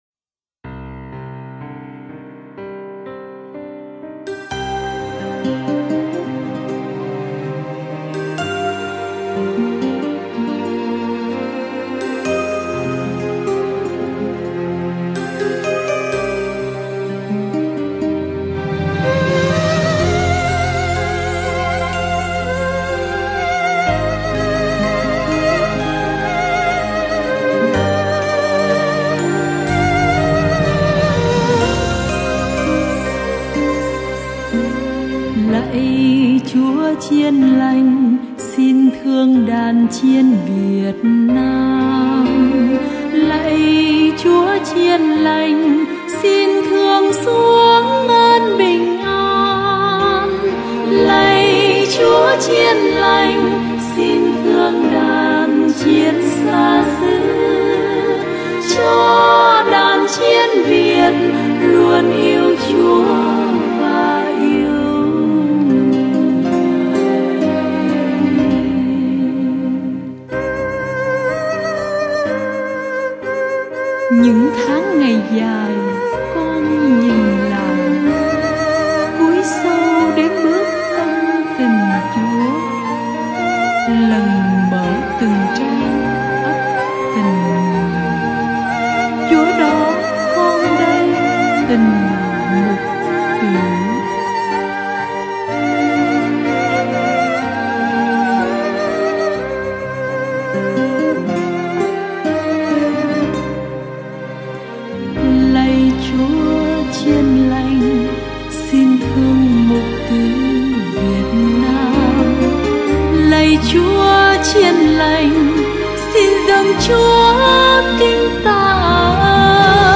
Thánh Ca: CHÚA CHIÊN LÀNH.